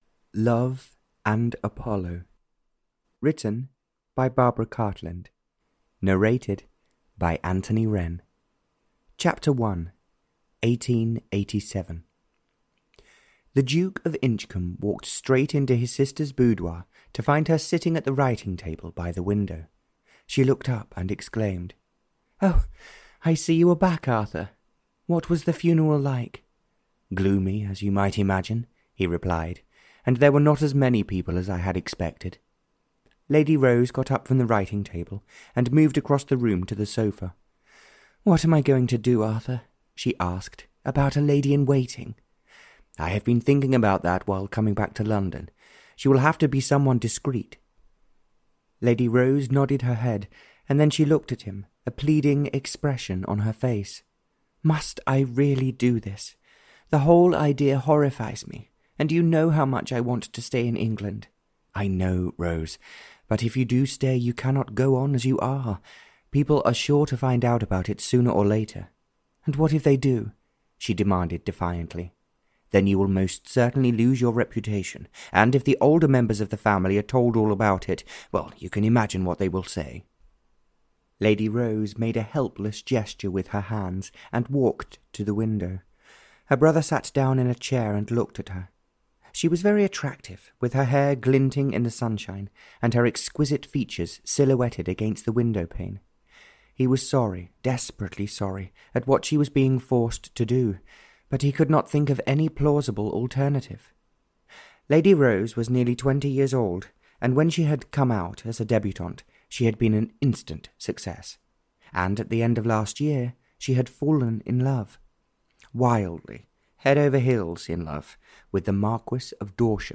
Love and Apollo (Barbara Cartland’s Pink Collection 57) (EN) audiokniha
Ukázka z knihy